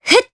Cleo-Vox_Casting1_jp.wav